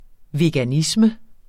Udtale [ veganˈismə ]